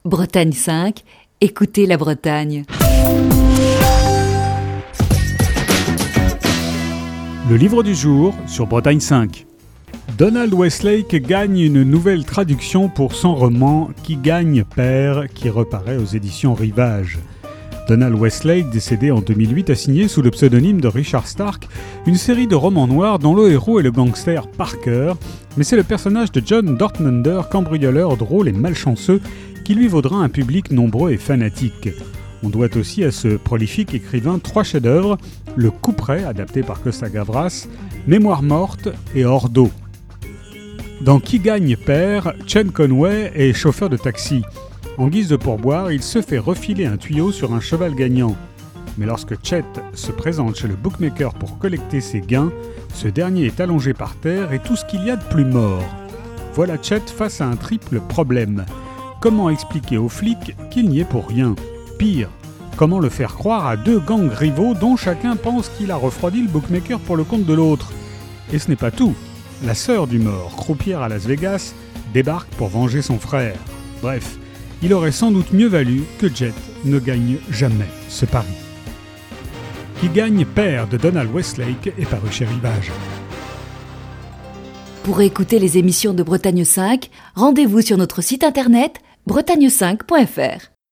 Chronique du 5 août 2021.